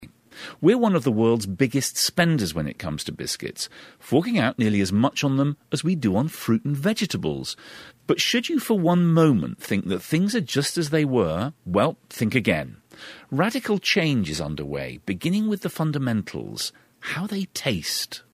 【英音模仿秀】饼干恒久远 滋味永不变 听力文件下载—在线英语听力室